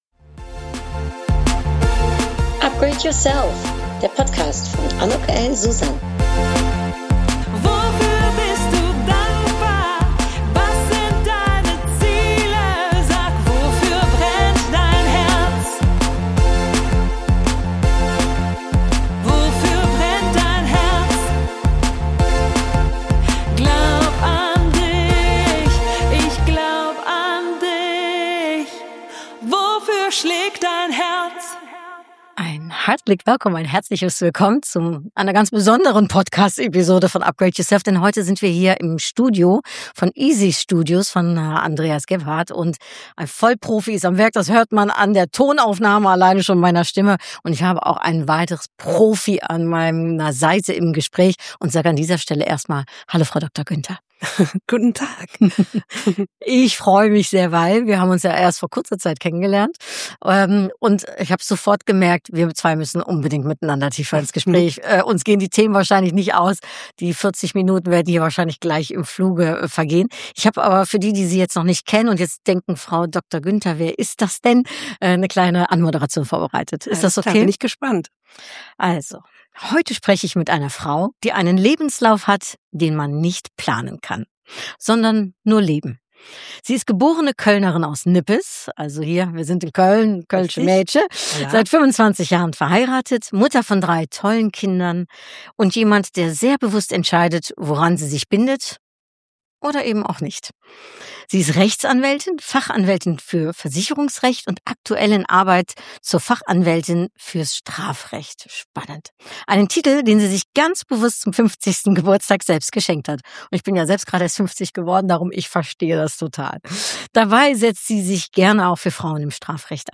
Aufgenommen haben wir das Gespräch ganz professionell in Köln-Mülheim